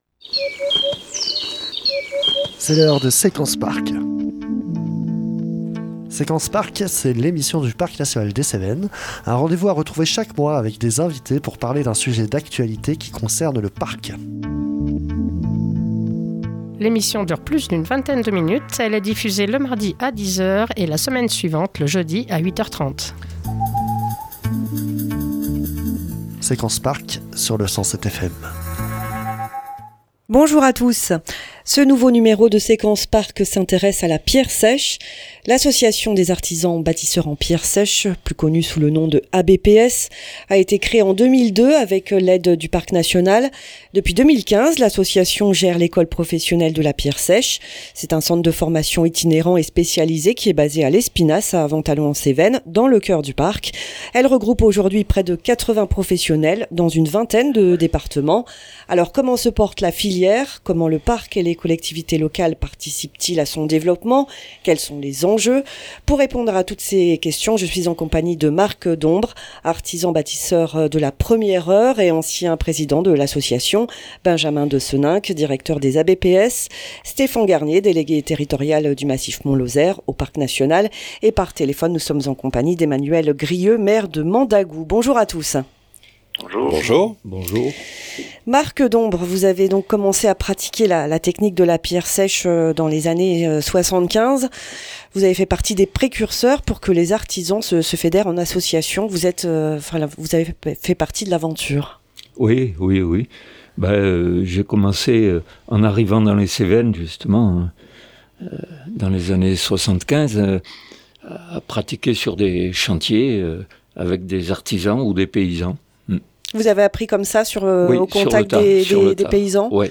par téléphone, Emmanuel Grieu, maire de Mandagout.